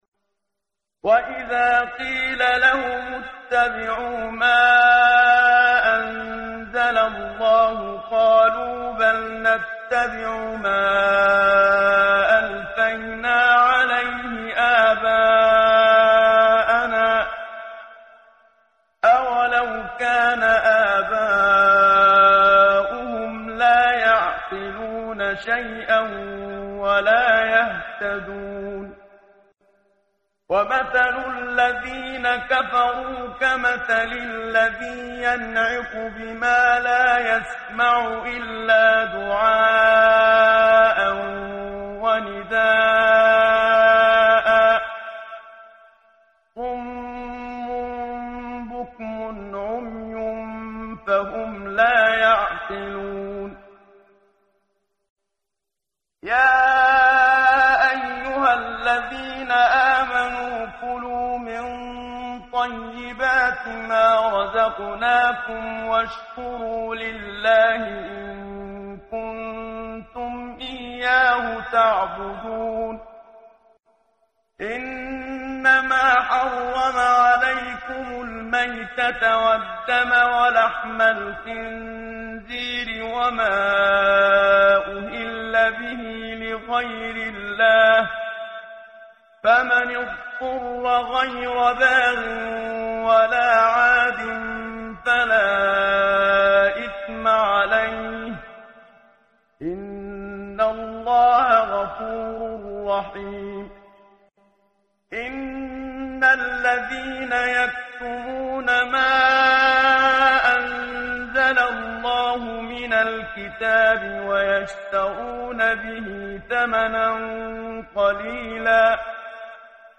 ترتیل صفحه 26 سوره مبارکه بقره (جزء دوم) از سری مجموعه صفحه ای از نور با صدای استاد محمد صدیق منشاوی